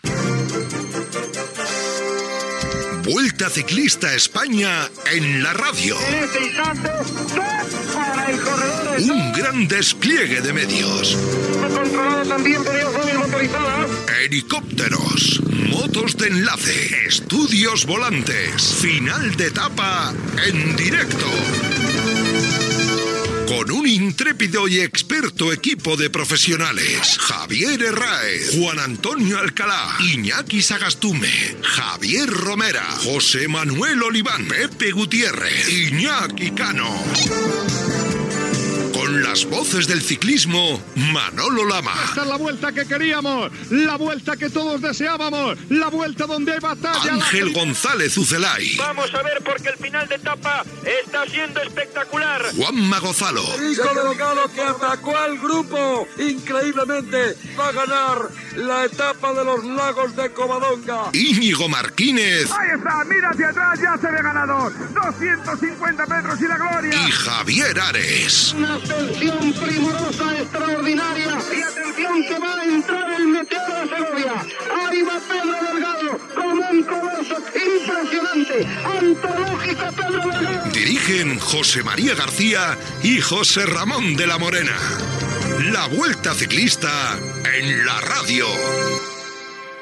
Promoció fictícia de la transmissió de la Vuelta Ciclista a España amb molts col·laboradors i la presentació de José Ramón de la Morena i José María García
Esportiu